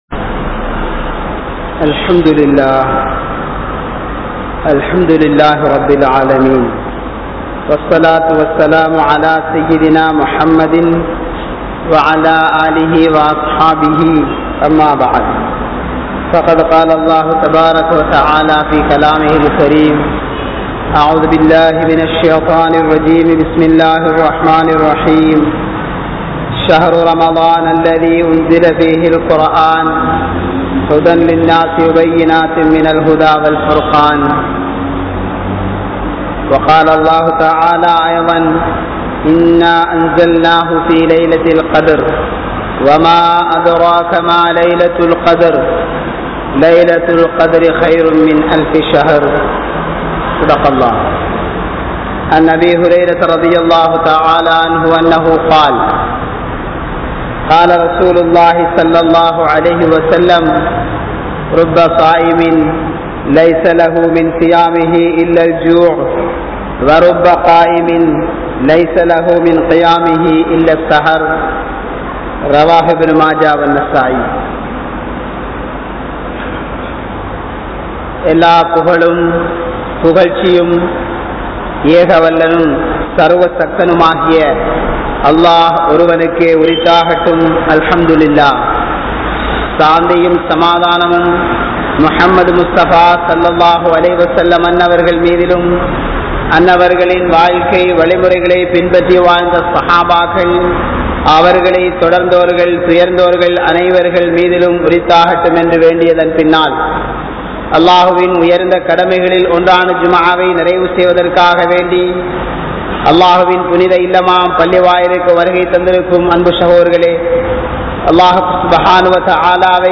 Iraththa Uravuhalai Searnthu Vaalungal (இரத்த உறவுகளை சேர்ந்து வாழுங்கள்) | Audio Bayans | All Ceylon Muslim Youth Community | Addalaichenai